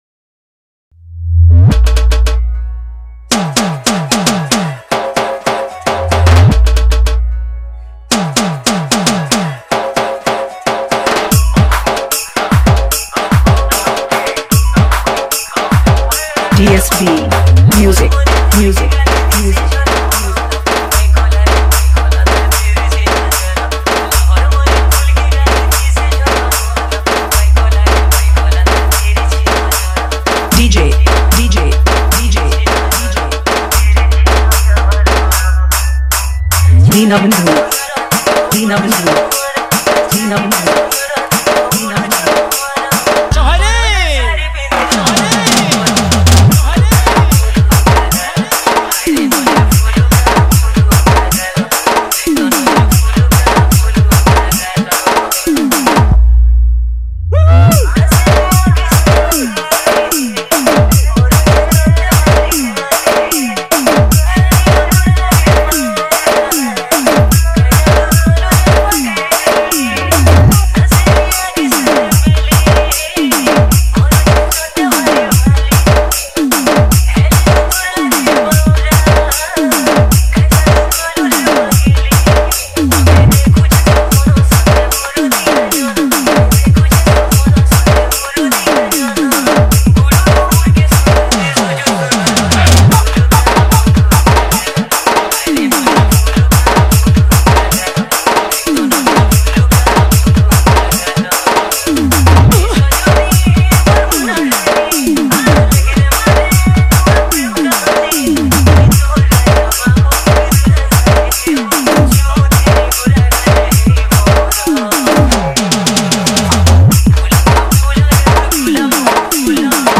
Sambalpuri Dj Song 2024
Category:  New Sambalpuri Dj Song 2023